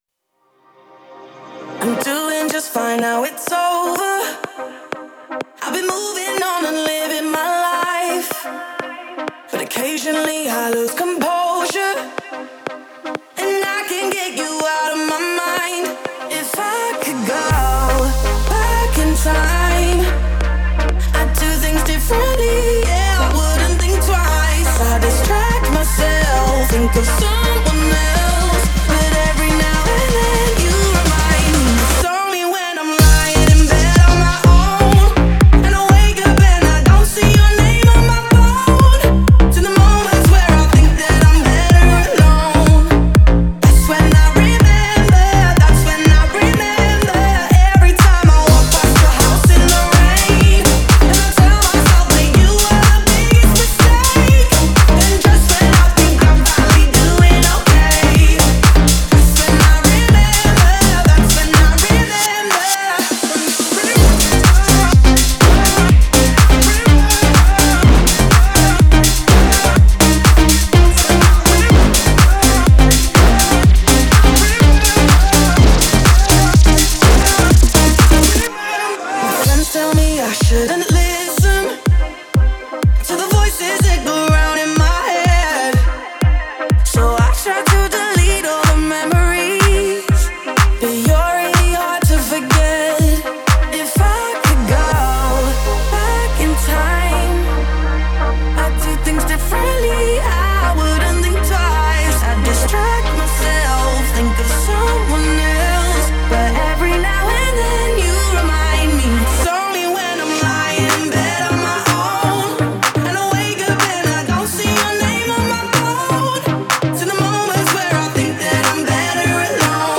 это энергичный трек в жанре EDM
мощные вокалы